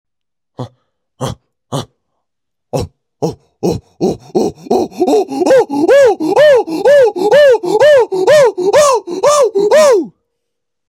Singe